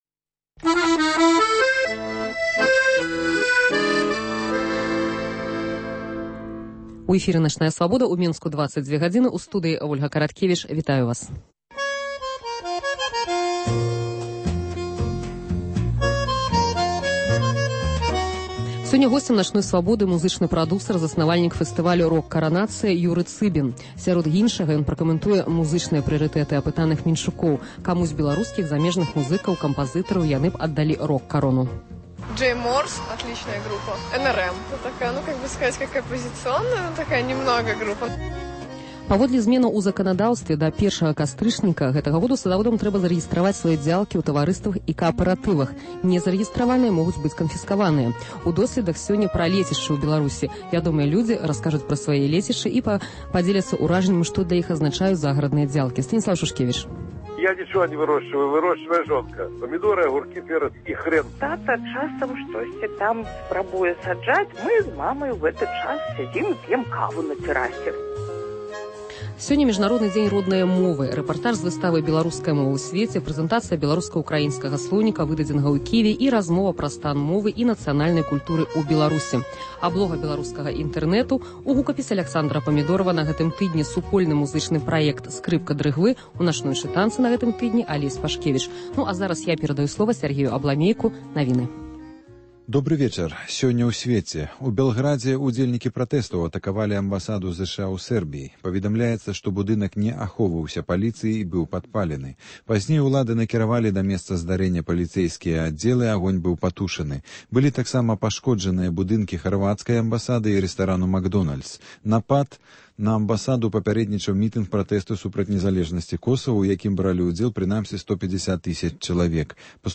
Рэпартаж з выставы “Беларуская мова ў сьвеце”.